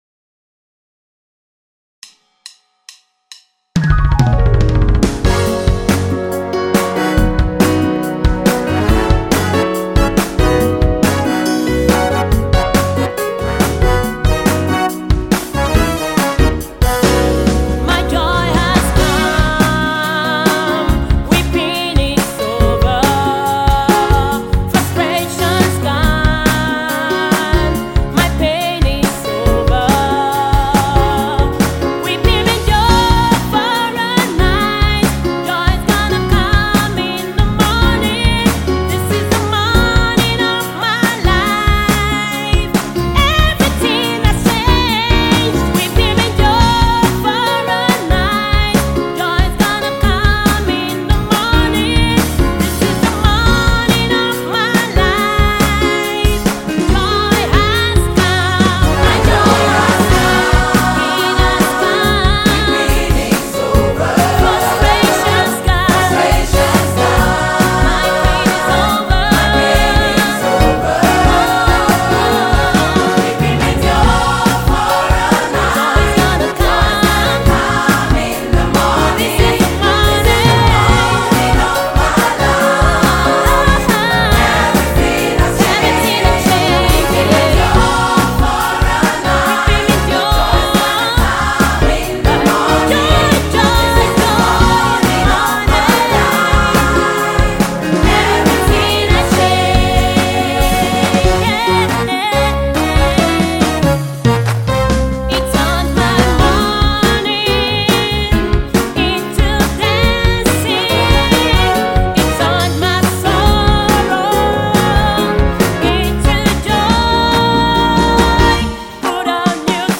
praise tune